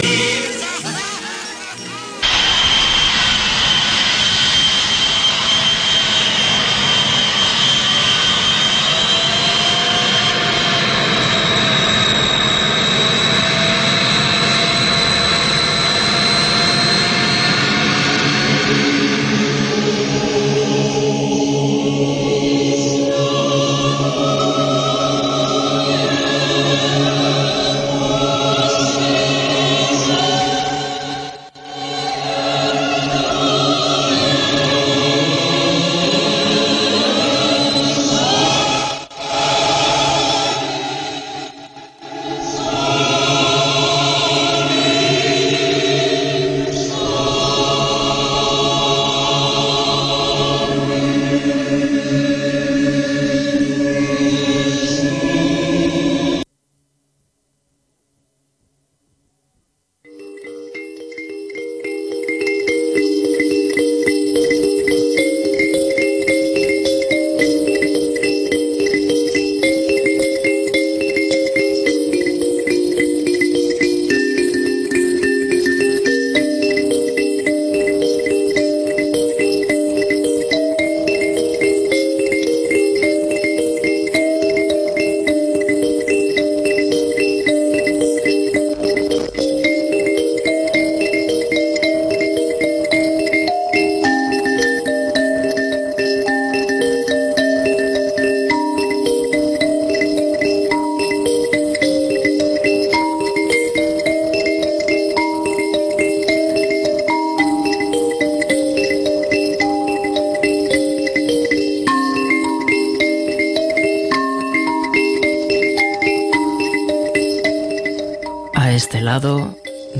Escuchamos un par de poemas de un autor preso, en esta ocasión el poeta turco Nazim Hikmet. Nos preguntamos también acerca del tabú hacia las relaciones humanas con los animales de compañía: escuchamos a Rupert Sheldrake.
Nos despedimos en un arrebato multilingüe para llenar de letras el amor en el mundo.